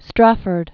(străfərd), First Earl of.